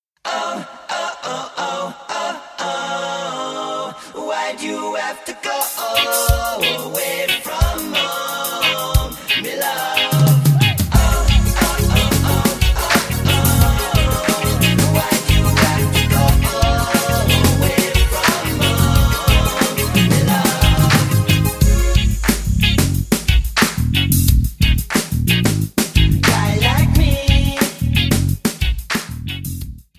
A#m
Backing track Karaoke
Pop, 2000s